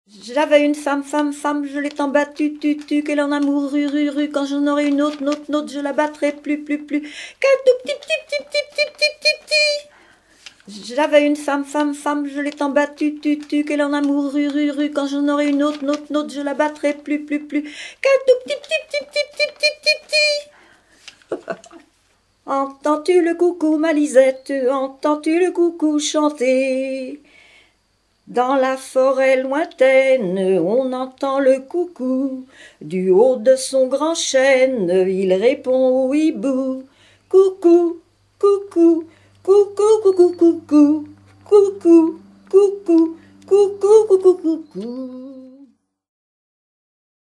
Mémoires et Patrimoines vivants - RaddO est une base de données d'archives iconographiques et sonores.
Imitations d'oiseaux